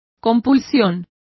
Complete with pronunciation of the translation of compulsion.